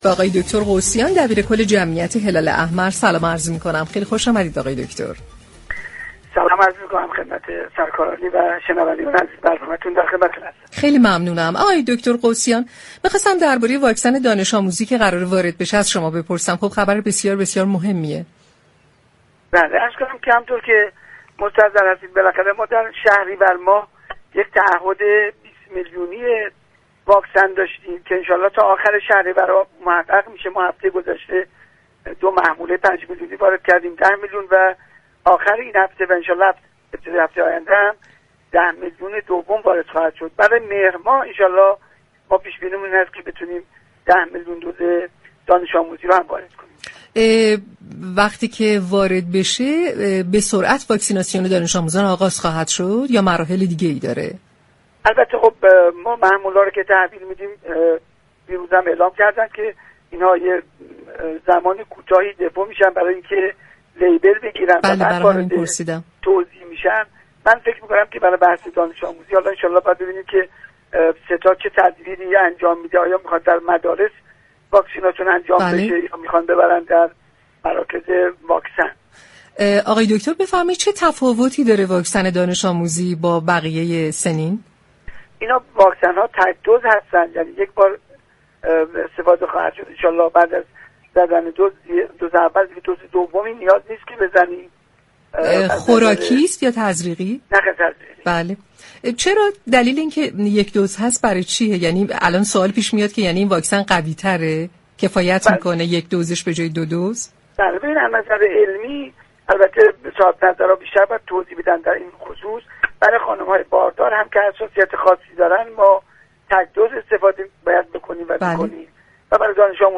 به گزارش پایگاه اطلاع رسانی رادیو تهران، محمدحسین قوسیان دبیركل جمعیت هلال احمر كشور در گفتگو با برنامه تهران ما سلامت رادیو تهران، درباره خبرهای منتشر شده در راستای واكسیناسیون دانش آموزی گفت: در شهریورماه تعهد 20 میلیون واكسن وارداتی را داشتیم كه هفته گذشته دو محموله 5 میلیونی واكسن وارد كشور شد و آخر هفته جاری و در هفته آینده هم 10 میلیون دُز واكسن وارد كشور می‌شود.